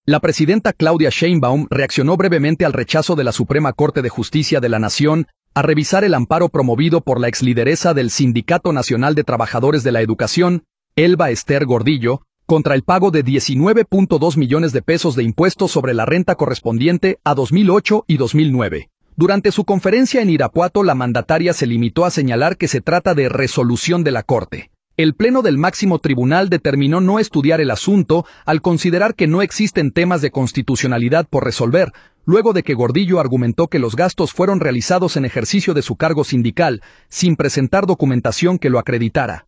Durante su conferencia en Irapuato, la mandataria se limitó a señalar que se trata de “resolución de la Corte”. El pleno del máximo tribunal determinó no estudiar el asunto al considerar que no existen temas de constitucionalidad por resolver, luego de que Gordillo argumentó que los gastos fueron realizados en ejercicio de su cargo sindical, sin presentar documentación que lo acreditara.